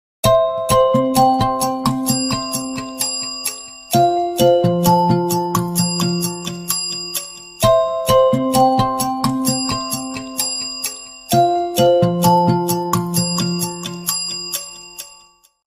Kategorien Telefon